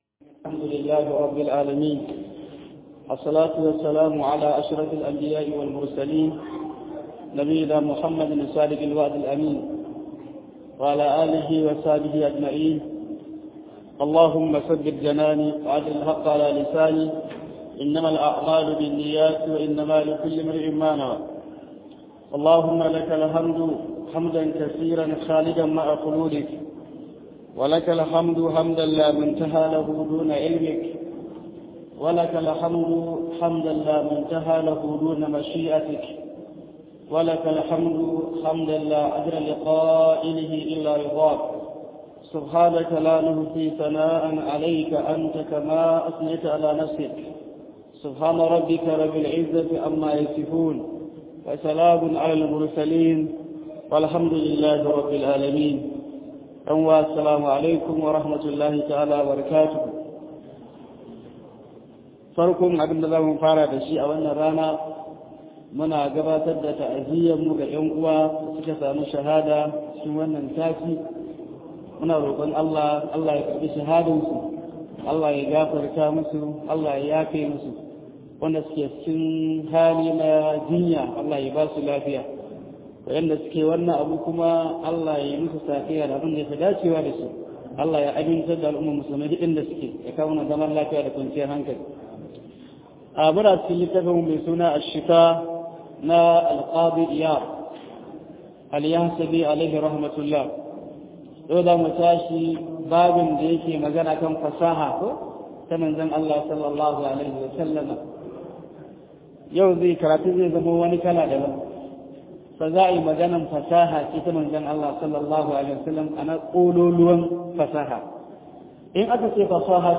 Fityanumedia Audios is a platform dedicated to sharing audio files of lectures from renowned Islamic scholars.